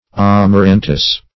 Search Result for " amarantus" : The Collaborative International Dictionary of English v.0.48: Amaranthus \Am`a*ran"thus\ ([a^]m`[.a]*r[a^]n"th[u^]s), Amarantus \Am`a*ran"tus\ ([a^]m`[.a]*r[a^]n"t[u^]s), n. Same as Amaranth .
amarantus.mp3